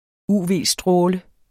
Udtale [ ˈuˀˈveˀˌsdʁɔːlə ]